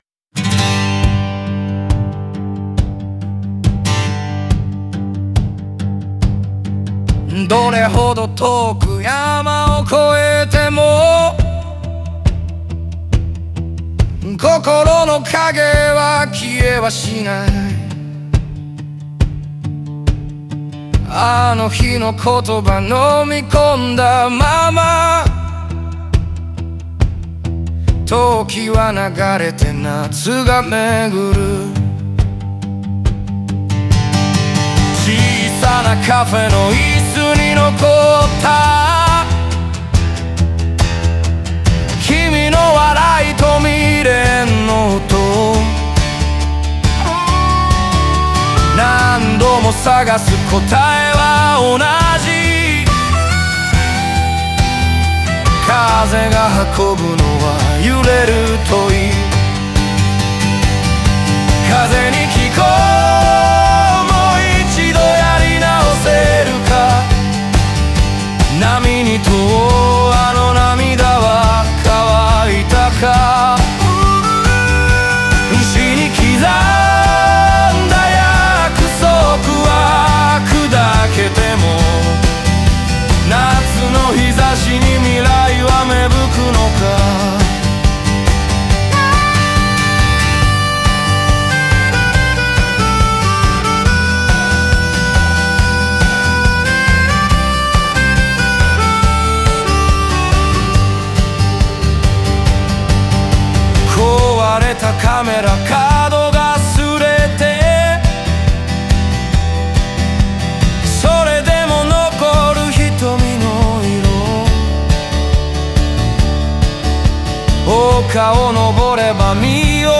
その切実さをフォークのシンプルな響きに重ね、未練と希望を同時に抱く人間の姿を浮かび上がらせています。